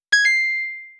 sfx_point.wav